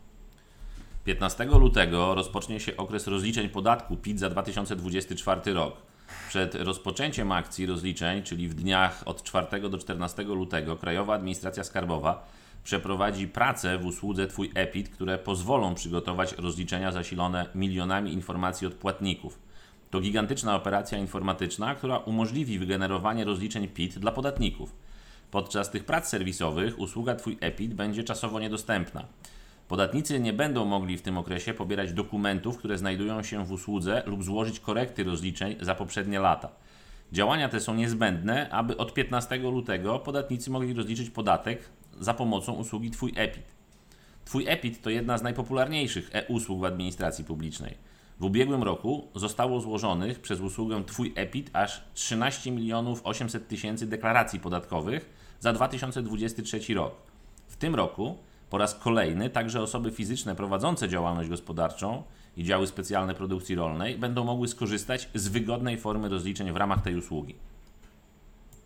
Przerwa w usłudze Twój e-PIT (wypowiedź